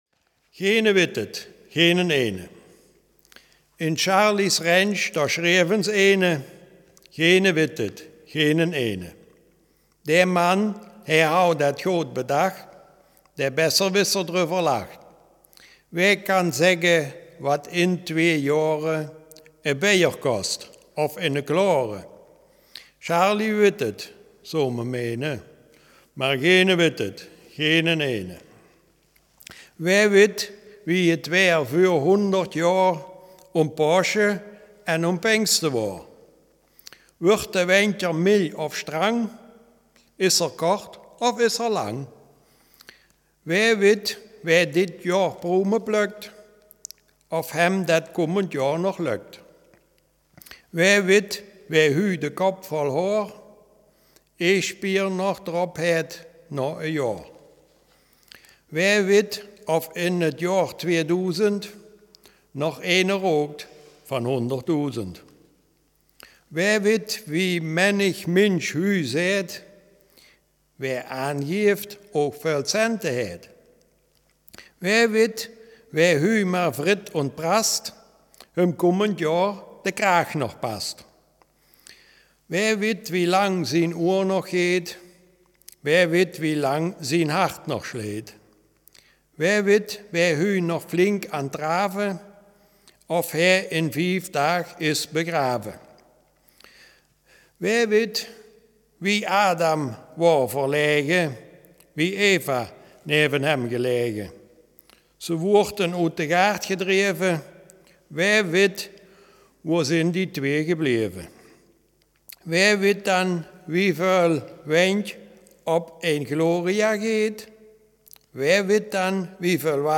Selfkant-Platt